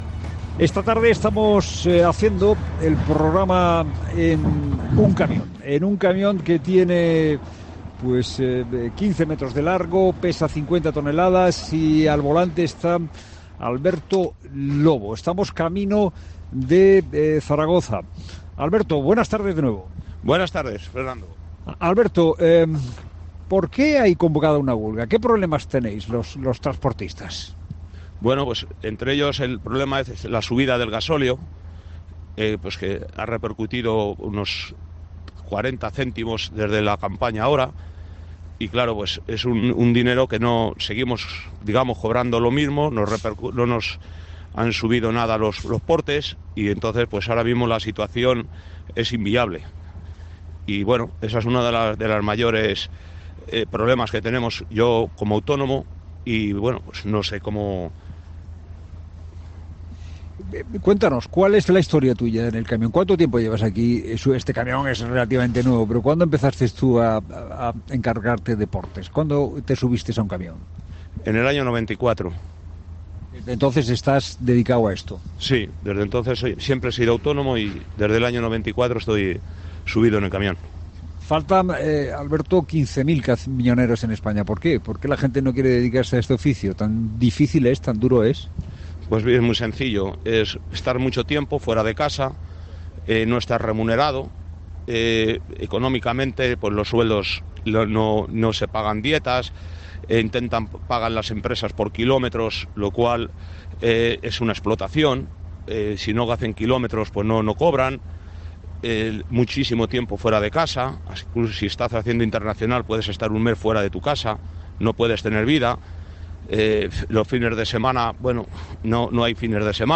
desde un camión camino a Zaragoza